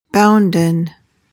PRONUNCIATION: (BOUN-duhn) MEANING: adjective: Obligatory; binding.